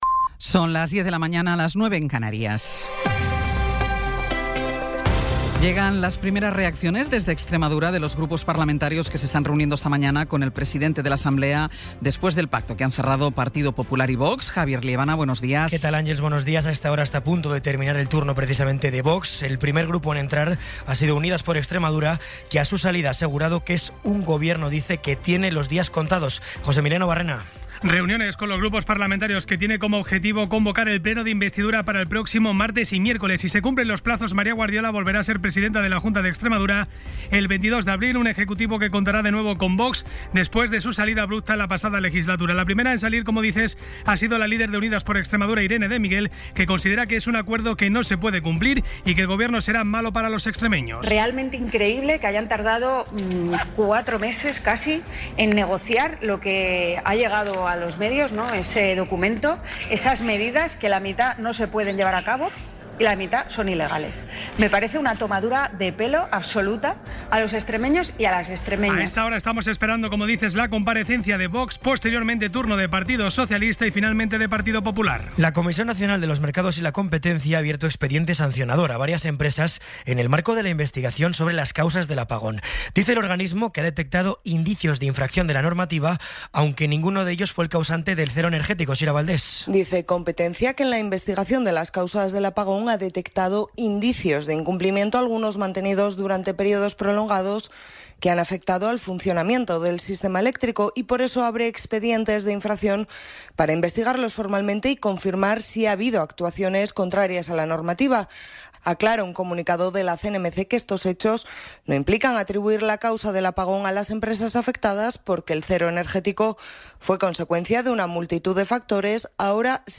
Resumen informativo con las noticias más destacadas del 17 de abril de 2026 a las diez de la mañana.